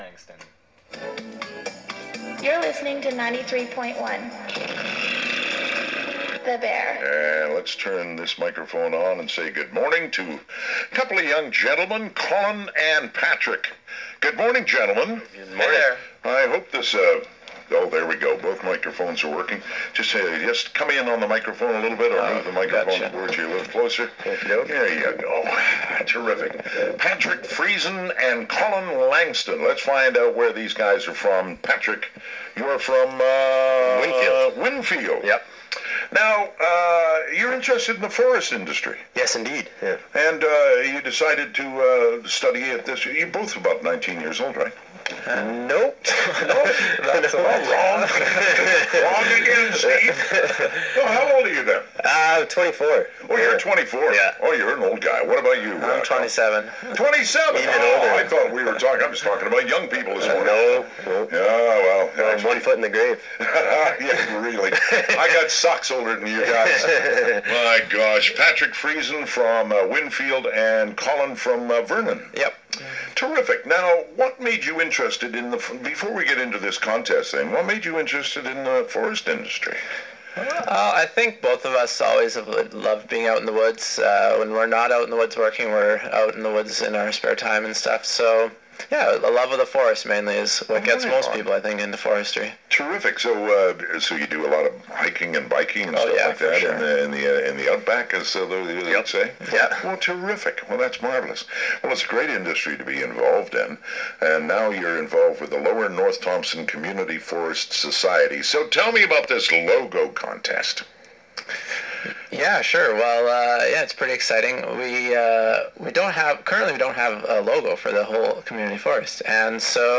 the-bear-interview.wav